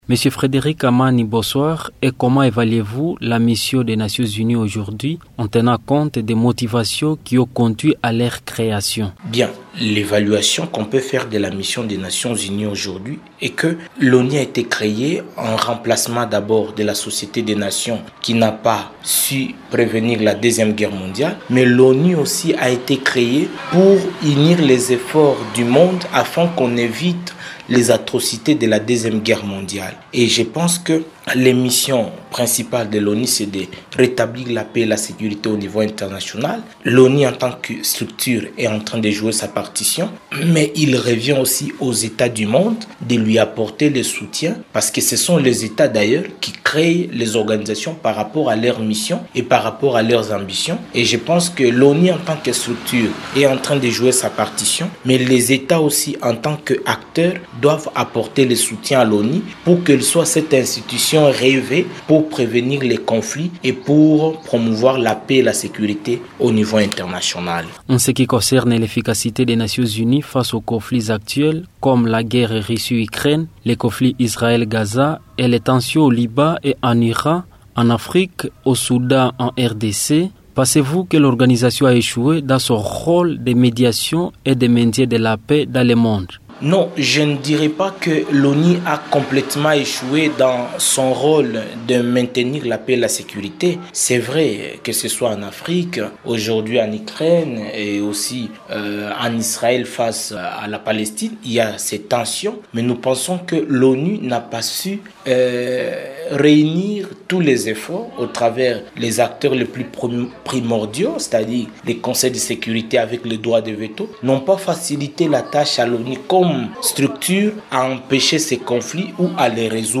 Dans cette interview, l’expert aborde les défis auxquels l’ONU fait face aujourd’hui, ainsi que les responsabilités des États membres dans le soutien à cette organisation.